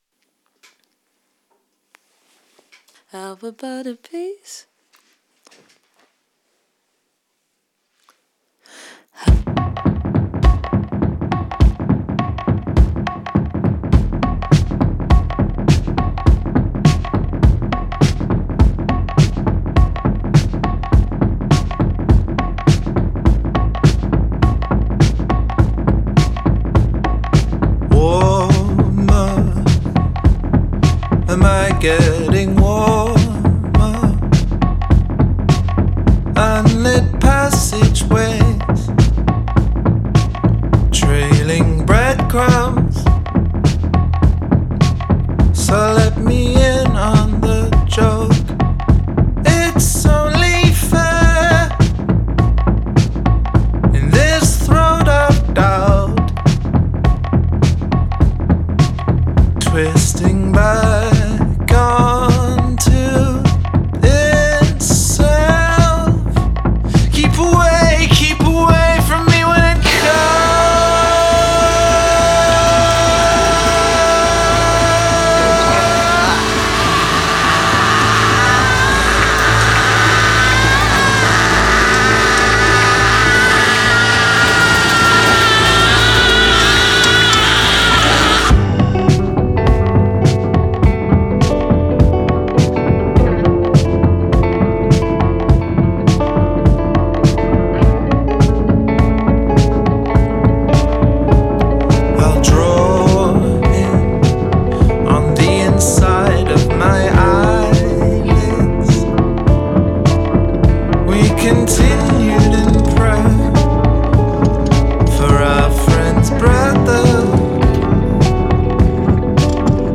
Même le pop rock peut être bien